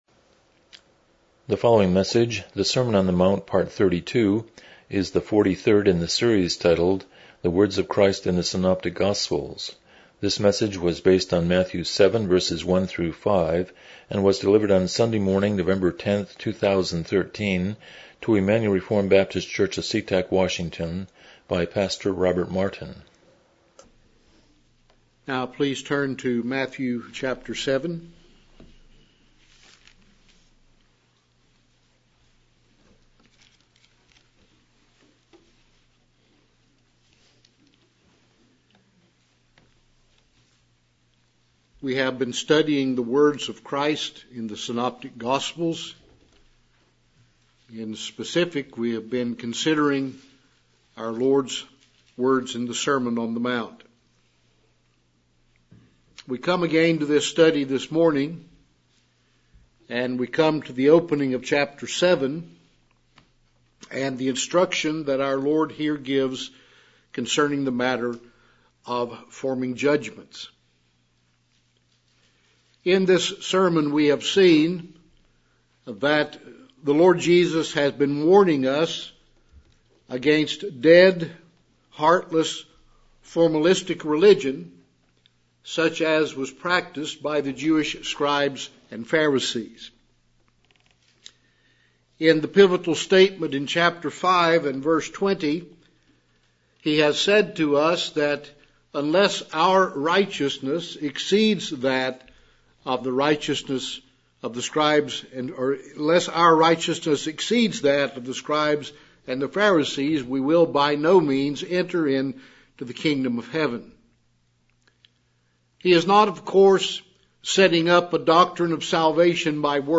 Passage: Matthew 7:1-5 Service Type: Morning Worship